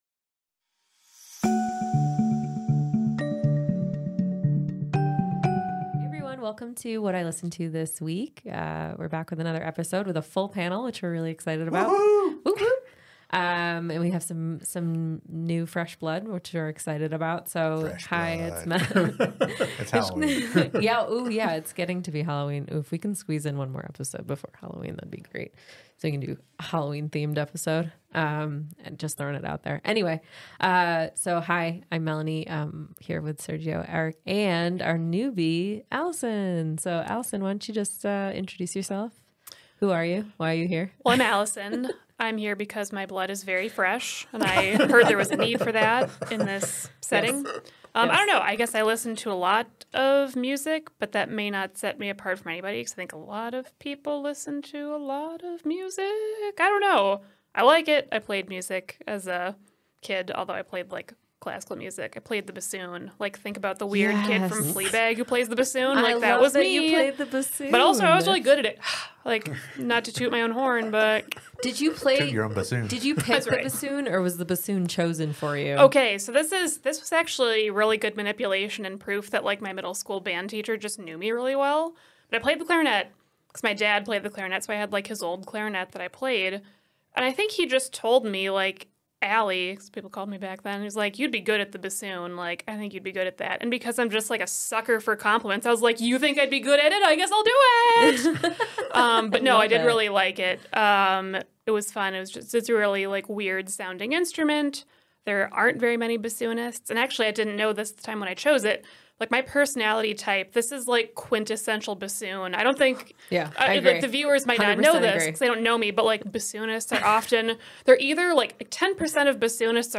Our panelists dissect each track, sharing their insights and appreciation for the diverse sounds showcased in this episode.